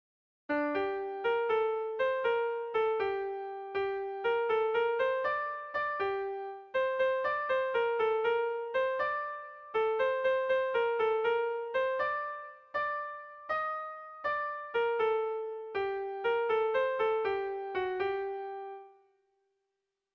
Bertso melodies - View details   To know more about this section
Erromantzea
ABDE